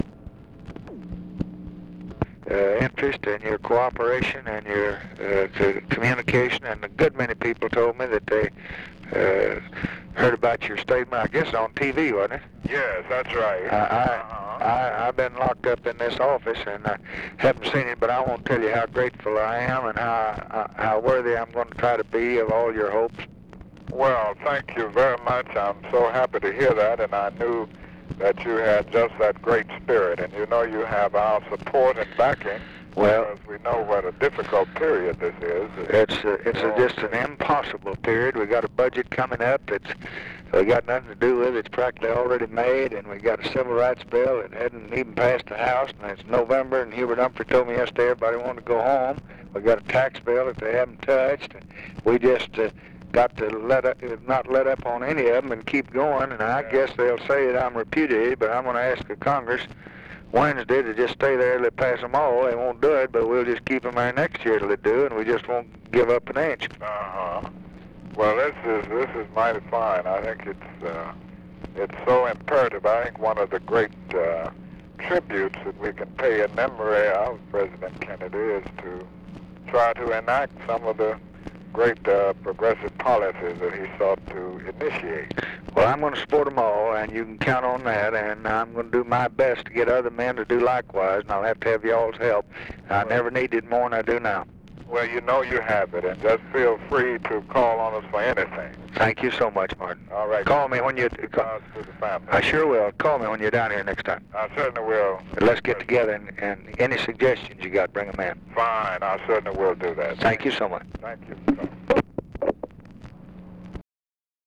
Conversation with MARTIN LUTHER KING, November 26, 1963
Secret White House Tapes